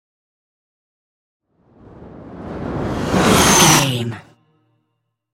Trailer dramatic raiser
Sound Effects
In-crescendo
Atonal
driving
futuristic
intense
tension
dramatic
riser